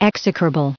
1370_execrable.ogg